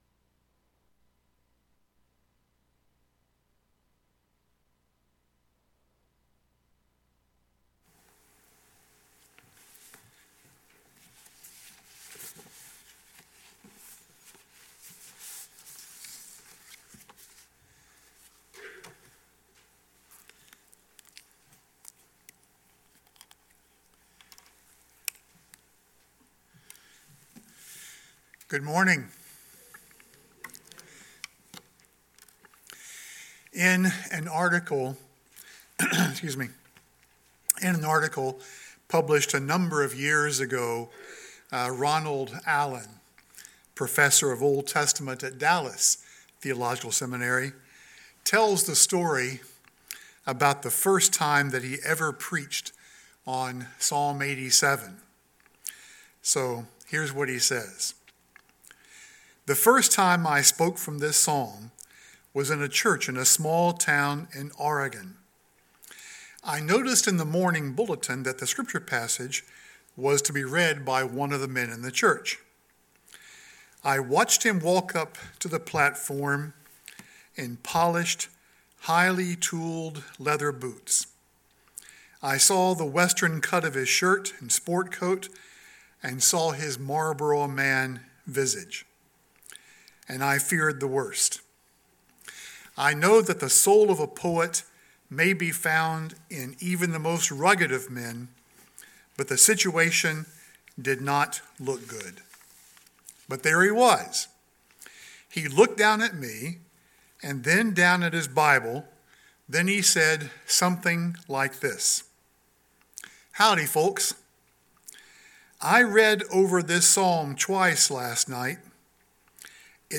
2024 Citizens of the Glorious City of God Preacher